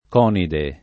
[ k 0 nide ]